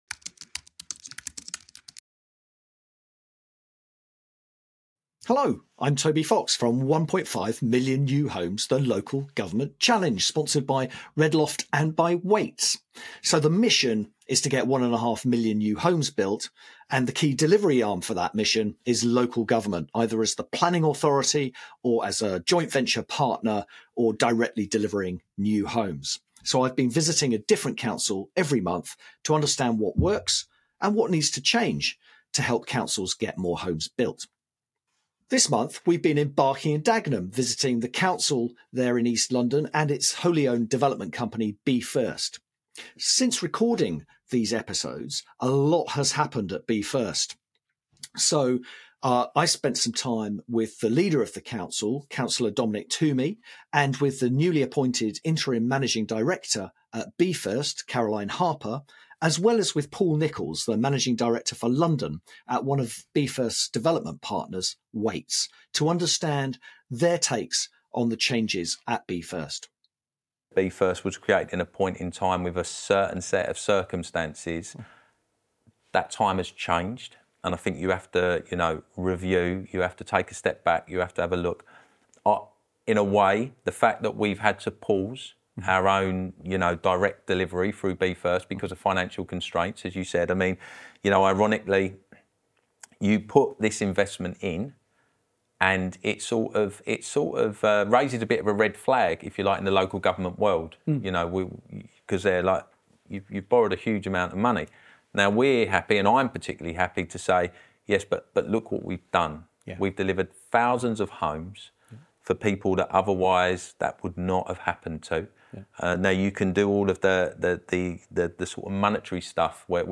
Leader of London Borough of Barking and Dagenham, councillor Dominic Twomey, describes the financial constraints forcing significant change at the Council’s wholly-owned development company Be First.